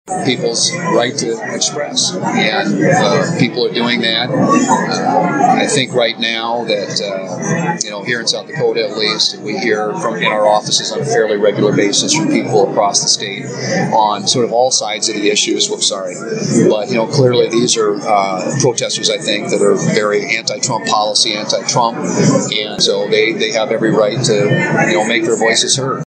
WATERTOWN, S.D.(KXLG)- U.S. Senator John Thune visited Watertown Thursday to address the local Rotary Club during their meeting held at the Elks Lodge.